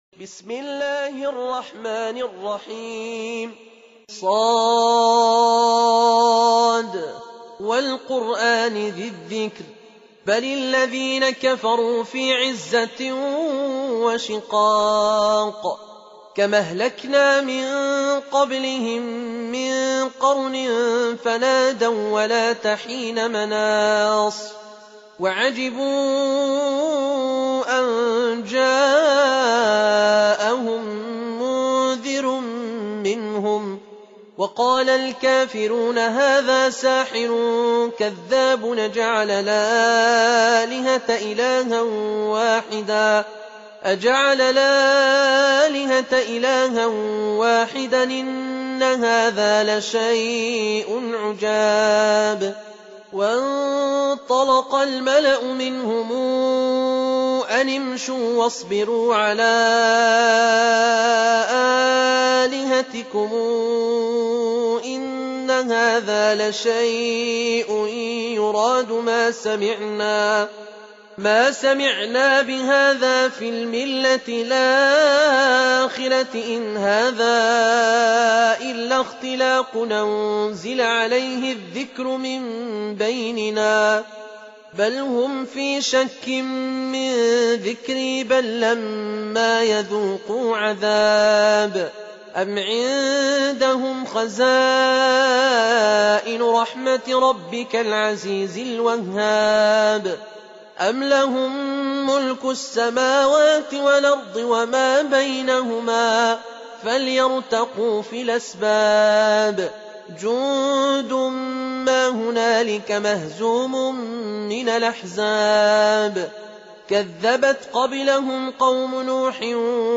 Surah Sequence تتابع السورة Download Surah حمّل السورة Reciting Murattalah Audio for 38. Surah S�d. سورة ص N.B *Surah Includes Al-Basmalah Reciters Sequents تتابع التلاوات Reciters Repeats تكرار التلاوات